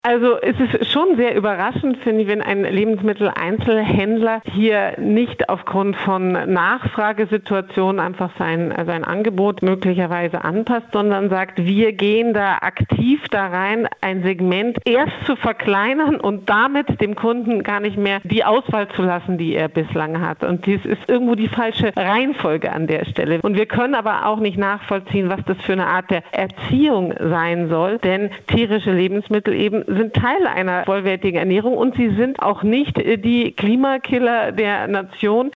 Interview: Bauernverband kritisiert Lidl-Zukunftspläne - PRIMATON